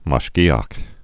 (mäsh-gēä)